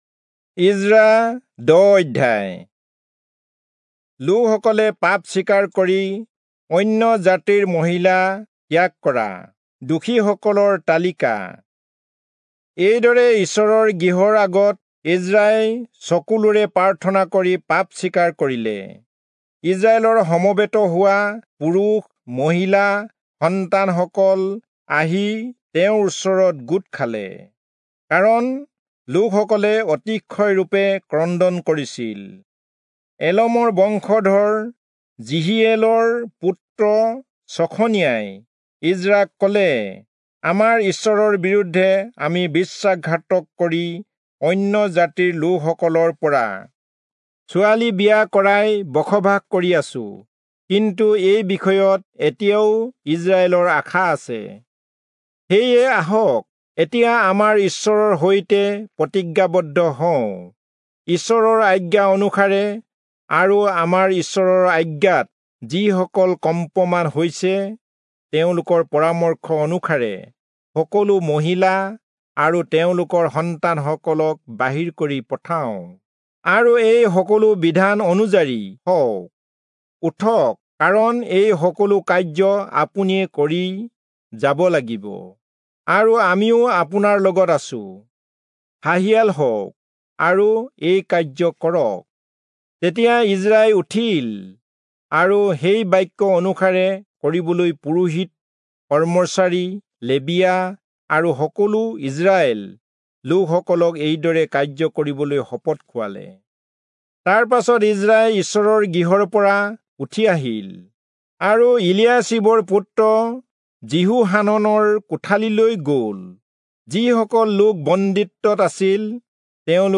Assamese Audio Bible - Ezra 2 in Bnv bible version